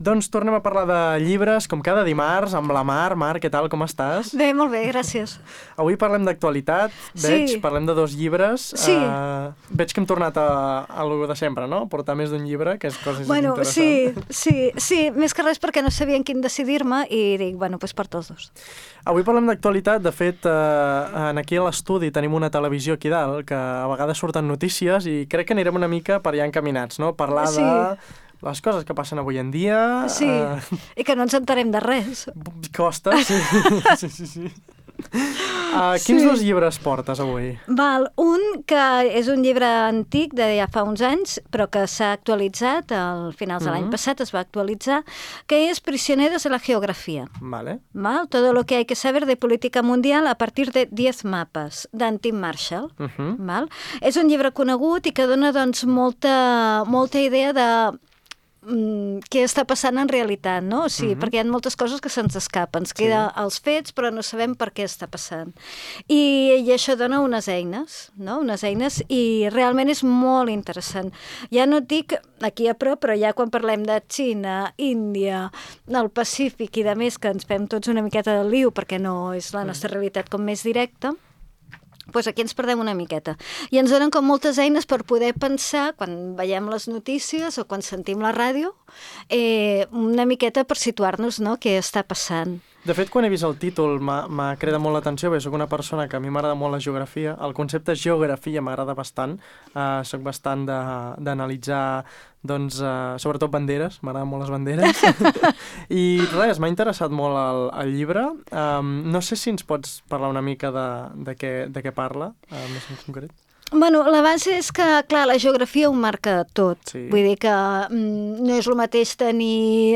Durant la conversa analitzem com la geografia continua condicionant la política internacional i el paper de les grans potències al món. També ens endinsem en les dinàmiques de poder, influència i estratègia que expliquen molts dels conflictes i moviments geopolítics actuals.